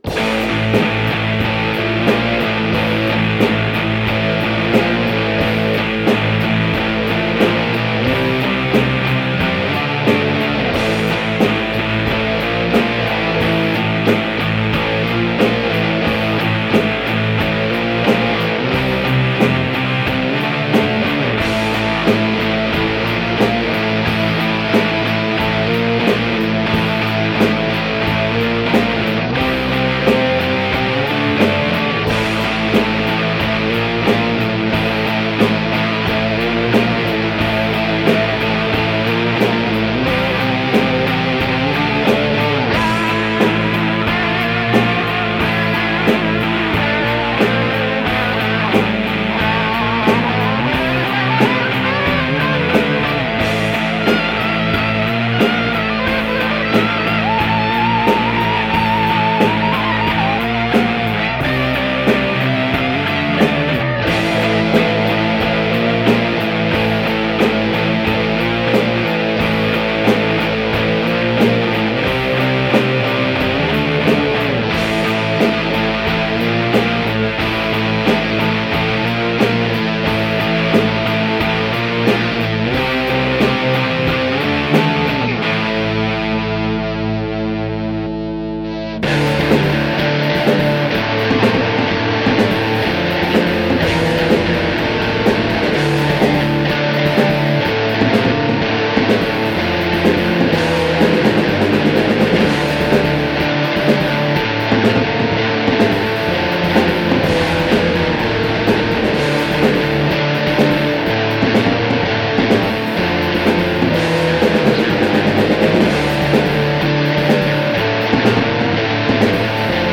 stoner_riff2-b.mp3